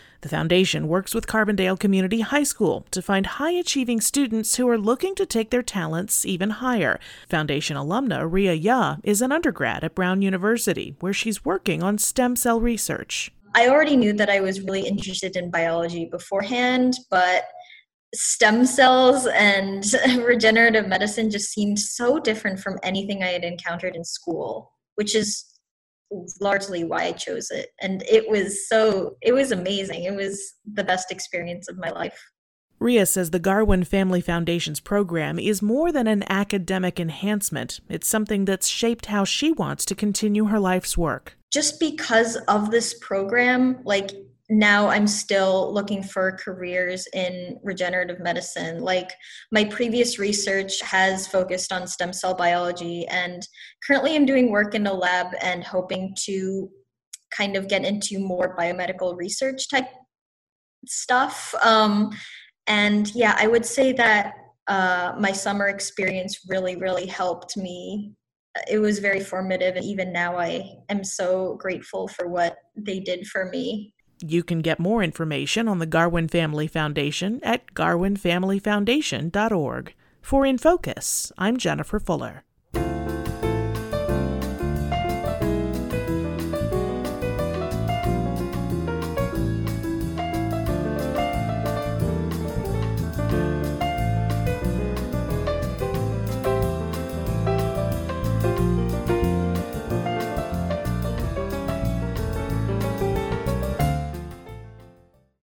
WSIU Radio "In Focus" interviews